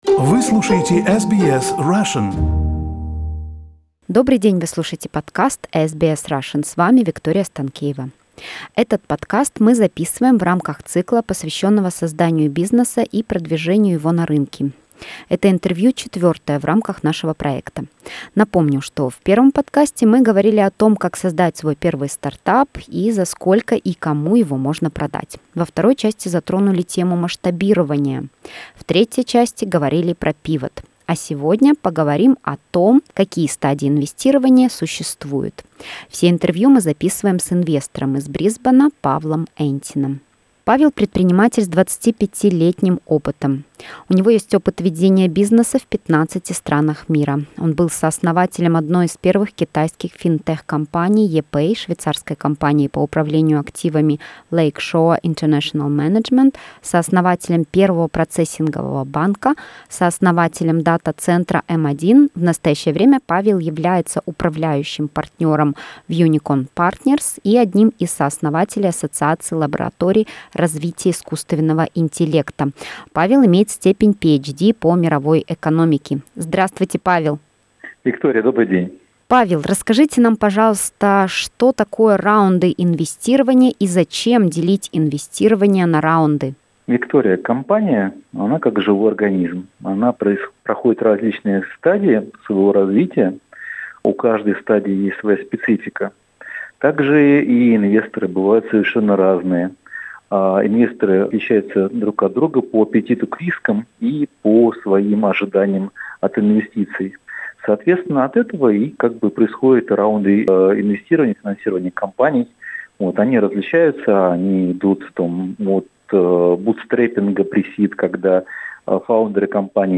Это четвертое интервью из цикла с советами для предпринимателей о том, как создавать, выращивать компании и продвигать их на рынке.